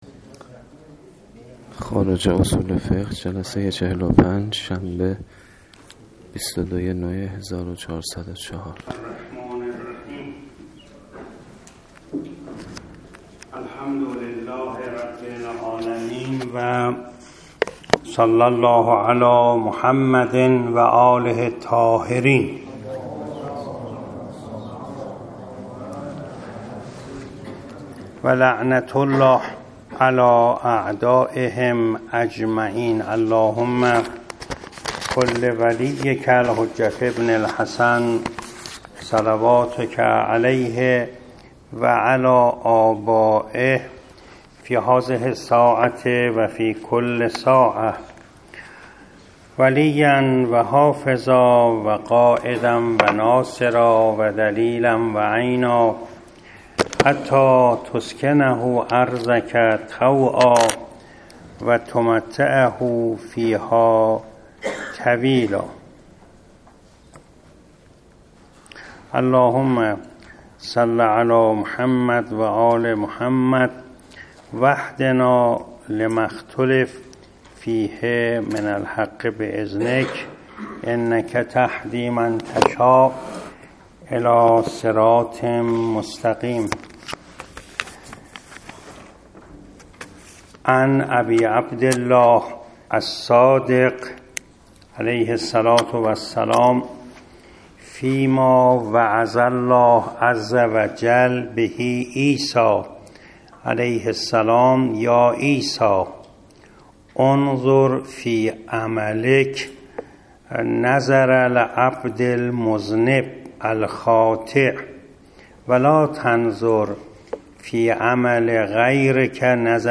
درس اخلاق